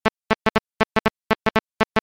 RF Interference Ringtone
An alert tone based on the interference noise caused by GSM radios.